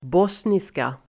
Ladda ner uttalet
bosniska substantiv, Uttal: [b'ås:nis:ka] Böjningar: bosniskanDefinition: endast singular språk i Bosnien-Hercegovina